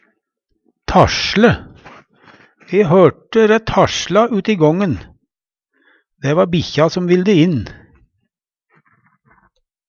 tasLe - Numedalsmål (en-US)